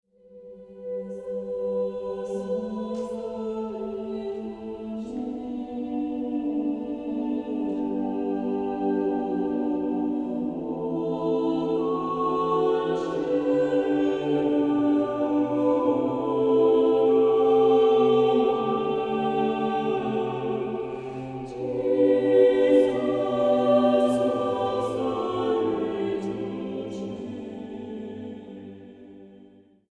Accompaniment:      Reduction
Music Category:      Choral